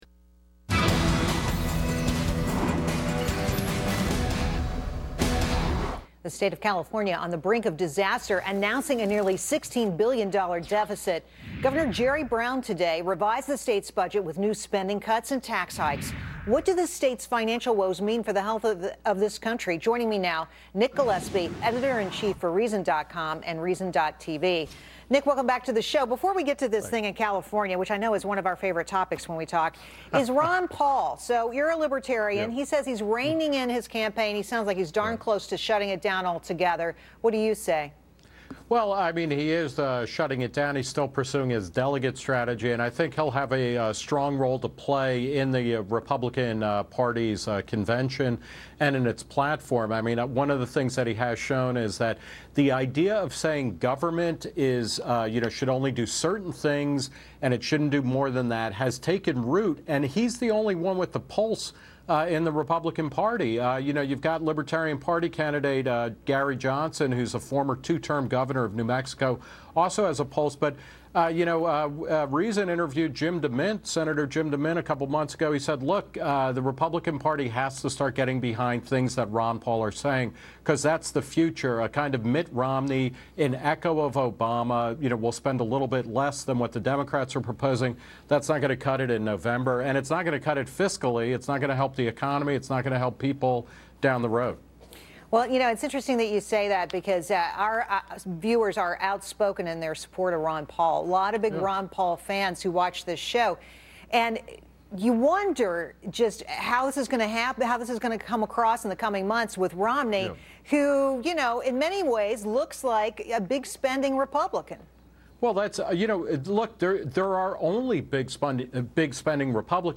Reason's Nick Gillespie discusses California's $16 billion deficit and Ron Paul's end to his active campaign with Gerri Willis on Fox Business' Willis Report.